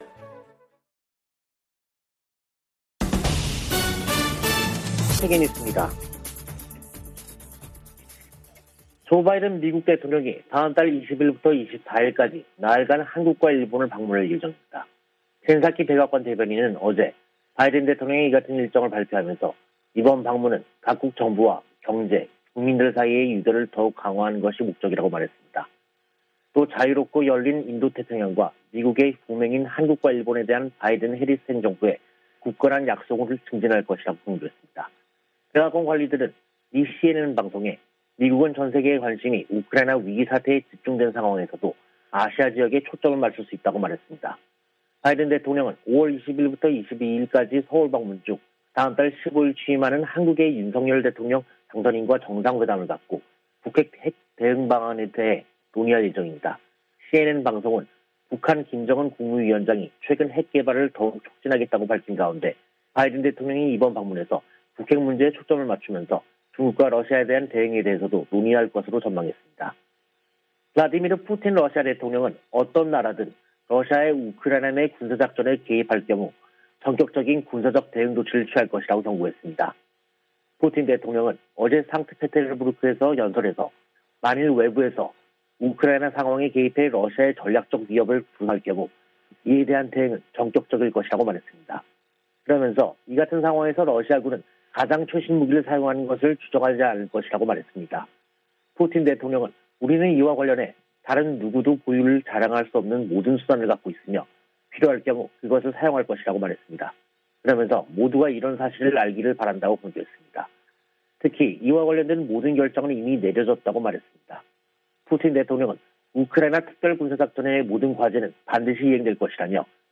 VOA 한국어 간판 뉴스 프로그램 '뉴스 투데이', 2022년 4월 28일 2부 방송입니다. 조 바이든 대통령이 다음 달 20일부터 24일까지 한국과 일본을 방문합니다. 한국의 윤석열 대통령 당선인 측은 바이든 대통령 방한을 환영하면서 포괄적 전략동맹이 강화되는 계기가 될 것으로 기대했습니다. 미 하원 청문회에서 '파이브 아이즈(Five Eyes)' 정보 동맹을 한·일 등으로 확대하는 문제가 거론됐습니다.